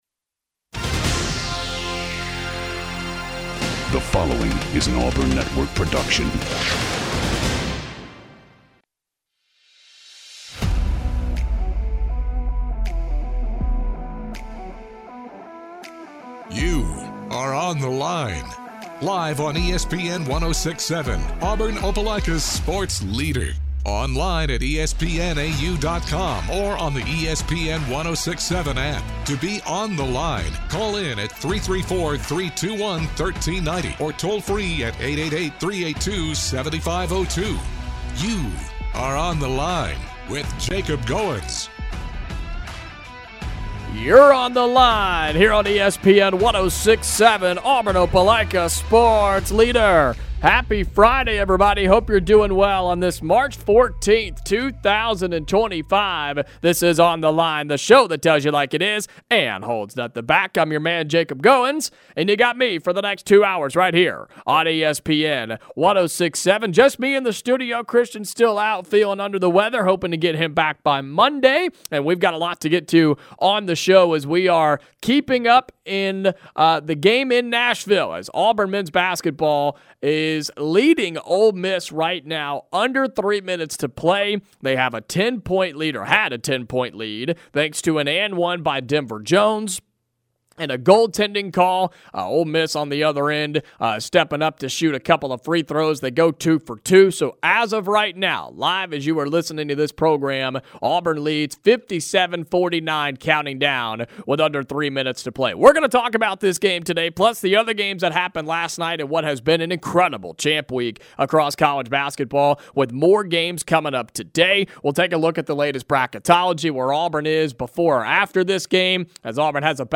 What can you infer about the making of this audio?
gives a live reaction to Auburn Basketball beating Ole Miss in the SEC Tournament Quarterfinals. he breaks down the win for the Tigers, and what it means for the rest of the trip in Nashville and for the NCAA Tournament.